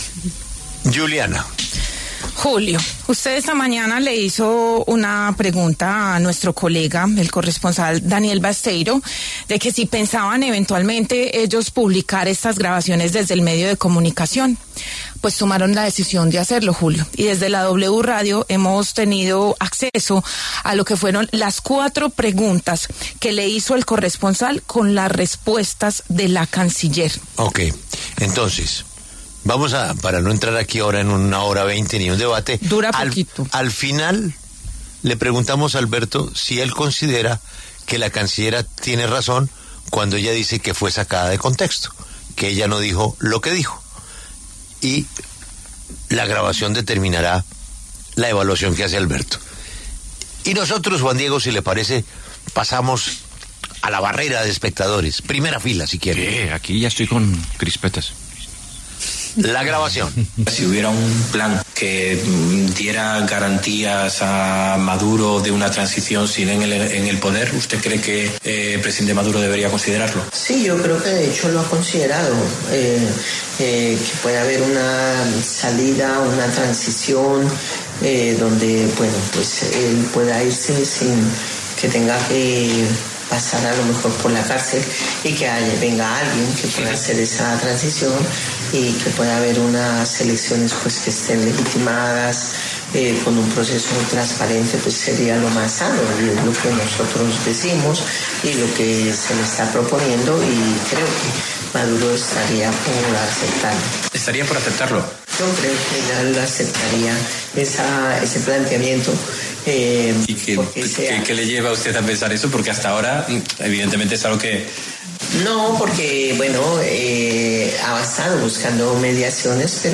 A través de un comunicado de prensa, el Ministerio de Relaciones Exteriores aclaró que la información producto de la entrevista otorgada por la canciller Rosa Villavicencio en Madrid, publicada por Bloomberg Línea, relacionada con un supuesto apoyo del Gobierno de Colombia a un plan de salida de Nicolás Maduro del poder, fue sacada de contexto.
Bloomberg hizo pública la grabación de la entrevista con la canciller Rosa Villavicencio, donde quedó claro qué fue lo que manifestó sobre un supuesto apoyo del Gobierno de Colombia a una salida del poder negociada de Nicolás Maduro.